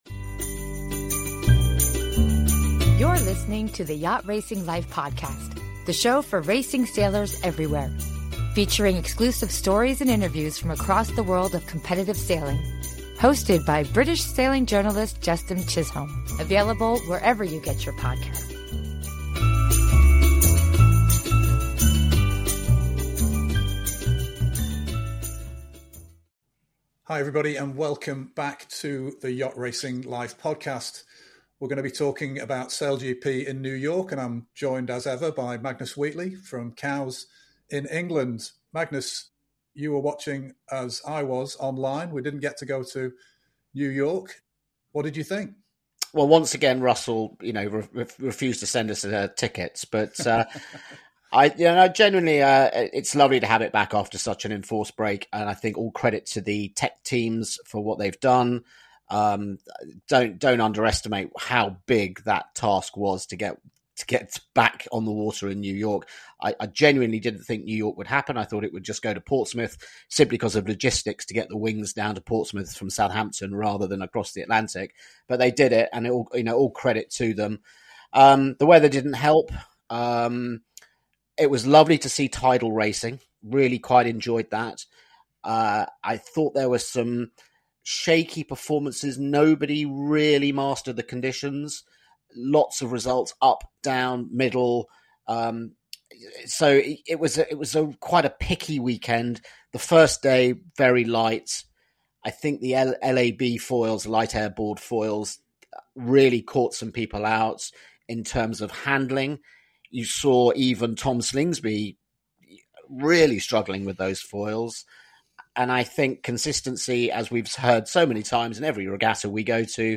A podcast for racing sailors everywhere. Exclusive interviews with the sport's top names.